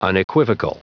Prononciation du mot unequivocal en anglais (fichier audio)
Prononciation du mot : unequivocal